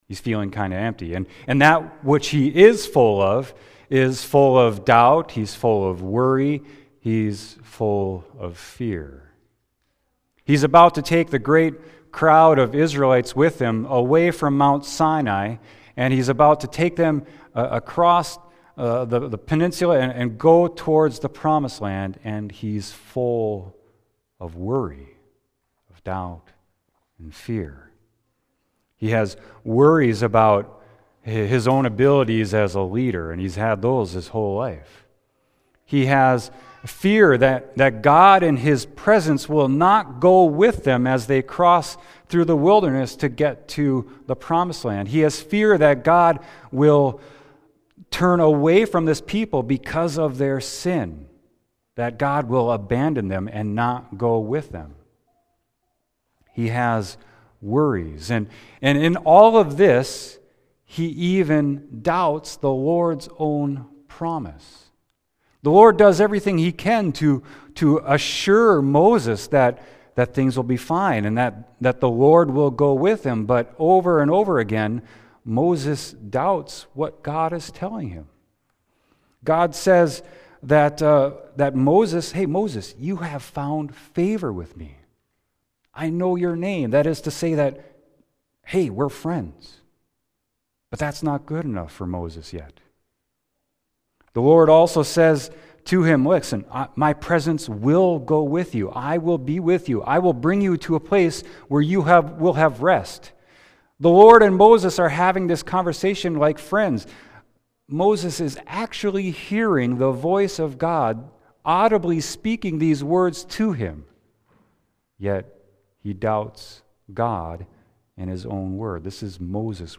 Sermon: Exodus 33.12-23